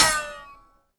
Bullet Ricochet
A bullet ricocheting off a metal surface with a high-pitched whine and spin
bullet-ricochet.mp3